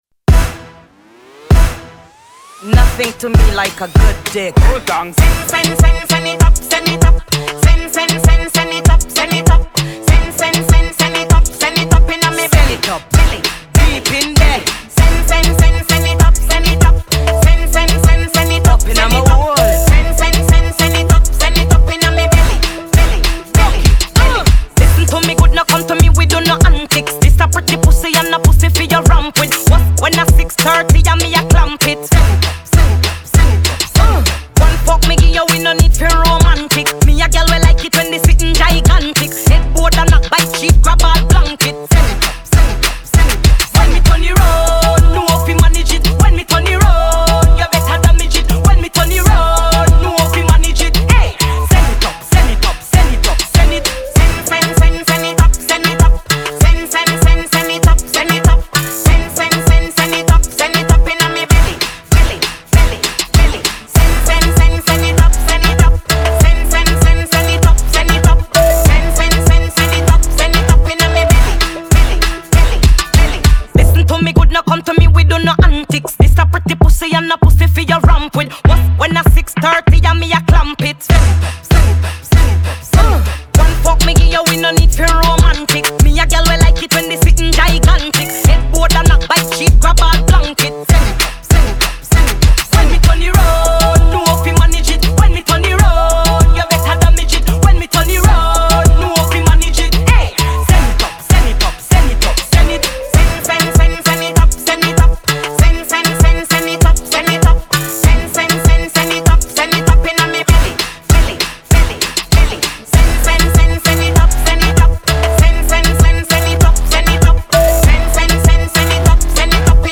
BPM98
MP3 QualityMusic Cut